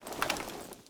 draw.ogg